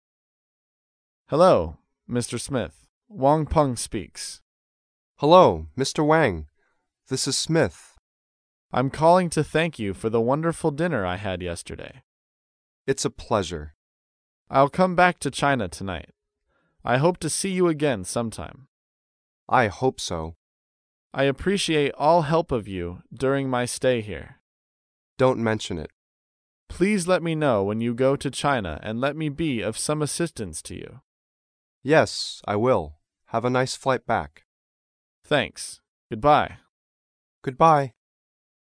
在线英语听力室高频英语口语对话 第109期:招待致谢(3)的听力文件下载,《高频英语口语对话》栏目包含了日常生活中经常使用的英语情景对话，是学习英语口语，能够帮助英语爱好者在听英语对话的过程中，积累英语口语习语知识，提高英语听说水平，并通过栏目中的中英文字幕和音频MP3文件，提高英语语感。